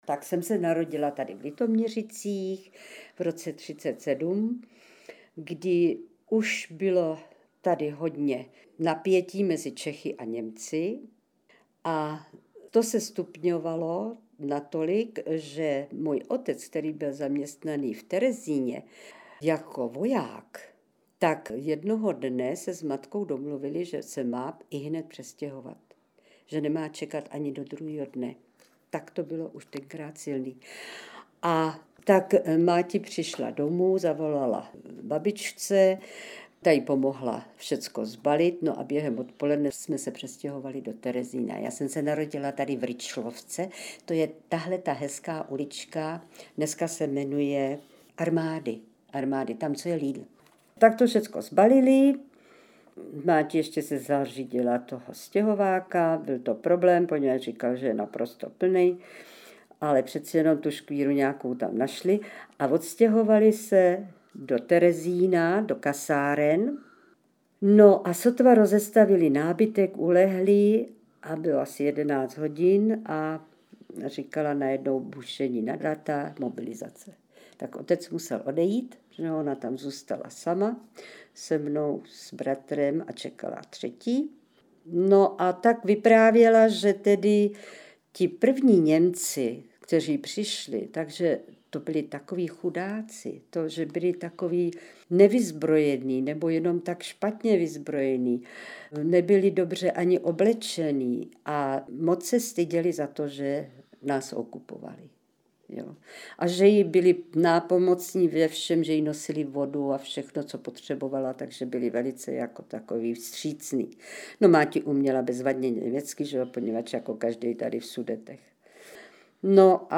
Jedná se o vzpomínky litoměřických rodáků.